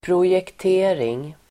Ladda ner uttalet
Uttal: [prosjekt'e:ring (el. -jekt-)]
projektering.mp3